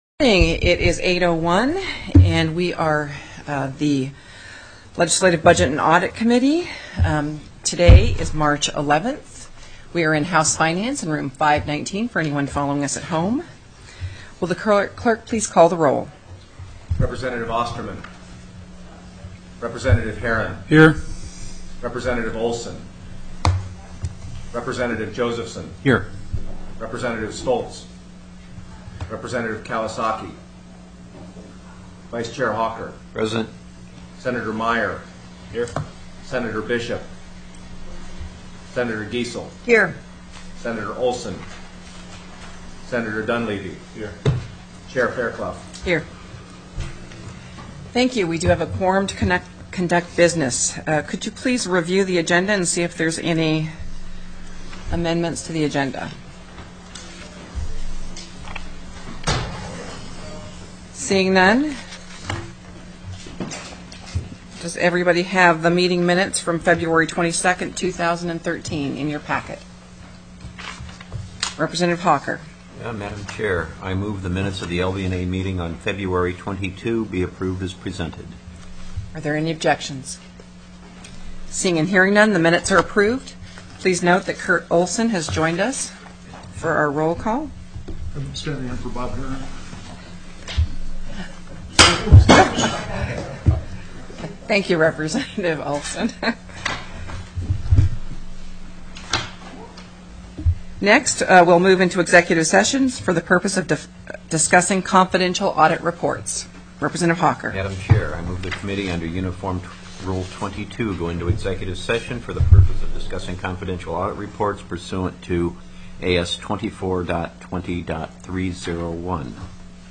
+ Other Committee Business TELECONFERENCED